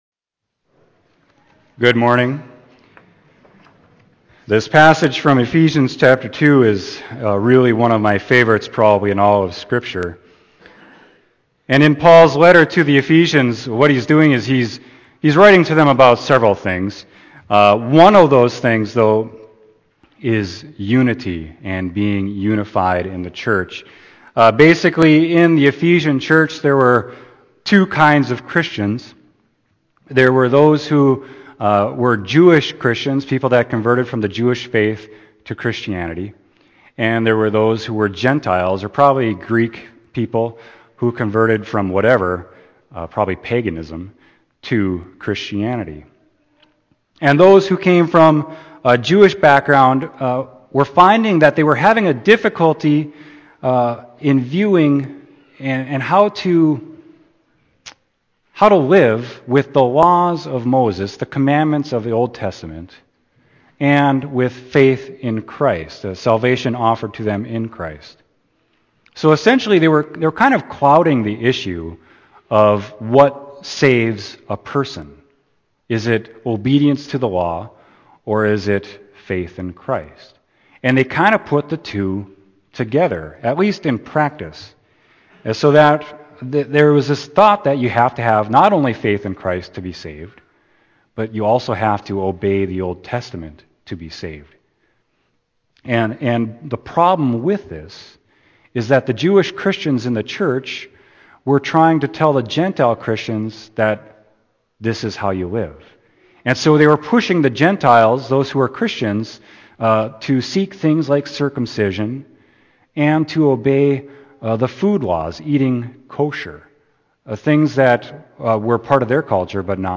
Sermon: Ephesians 2.1-10